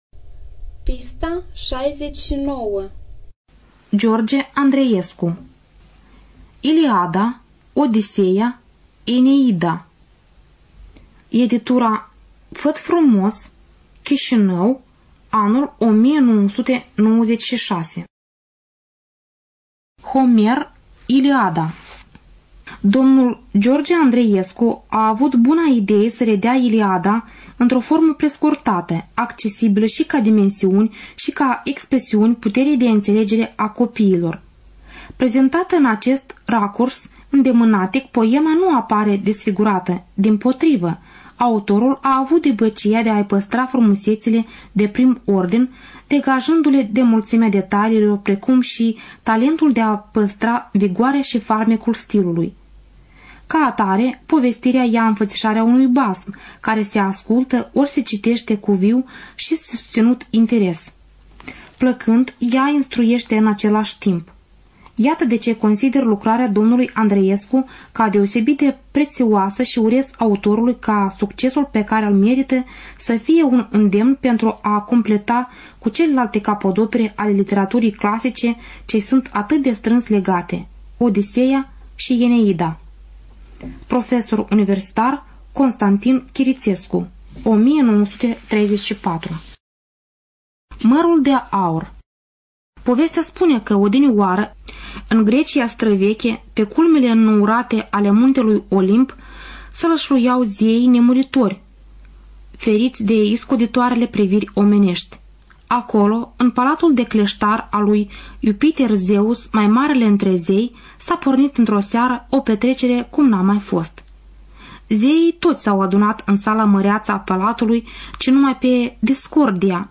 Студия звукозаписиНациональный Информационно-реабилитационный Центр Ассоциации Незрячих Молдовы